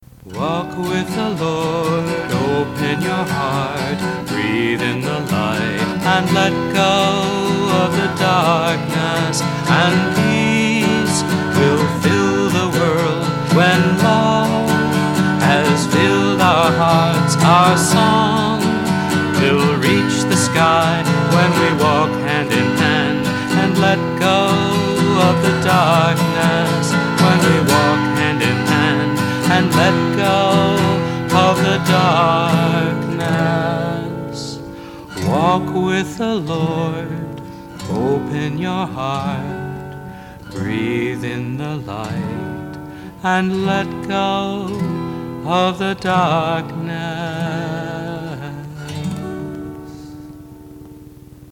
1. Devotional Songs
Major (Shankarabharanam / Bilawal)
8 Beat / Keherwa / Adi
Medium Fast
4 Pancham / F
1 Pancham / C
Lowest Note: p / G (lower octave)
Highest Note: P / G